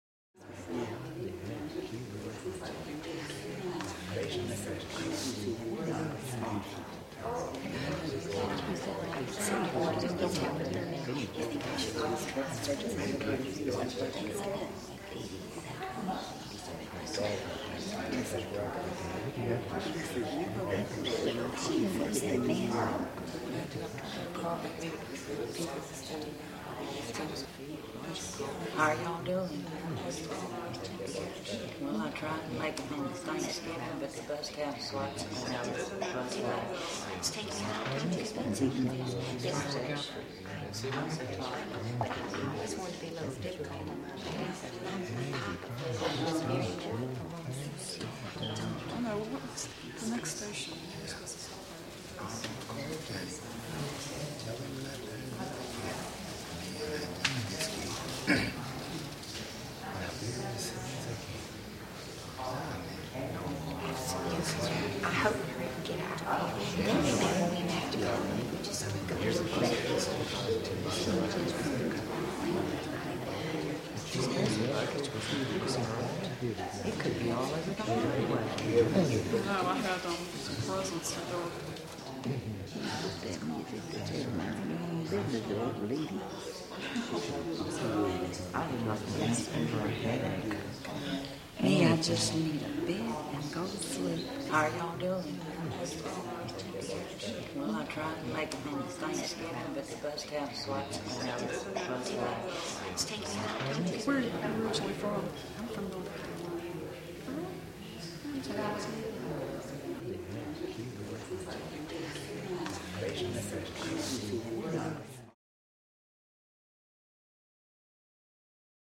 Звуки автовокзала
Люди ведут беседу у кассы